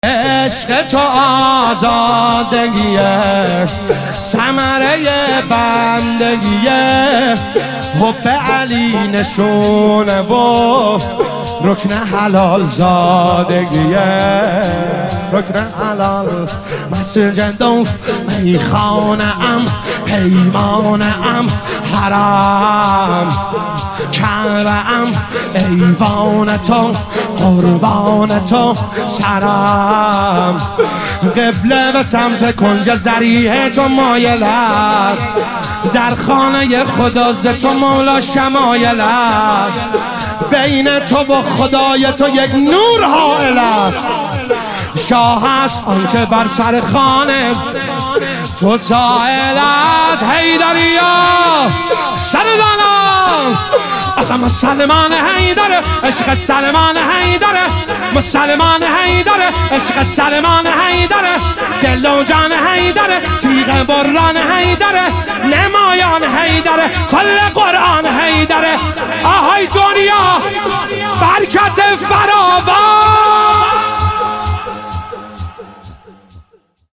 شهادت امام صادق علیه السلام 1401